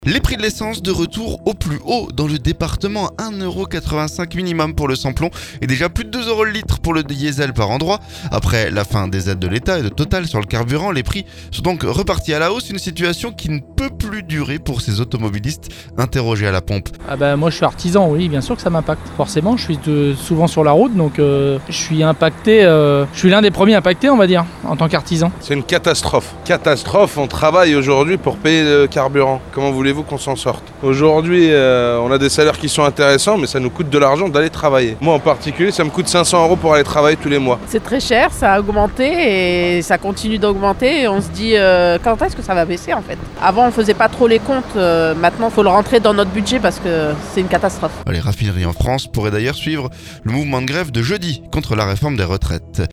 Après la fin des aides de l’Etat et de Total sur le carburant, les prix sont repartis à la hausse ces dernières semaines. Une situation qui ne peut plus durer pour ces automobilistes interrogés à la pompe…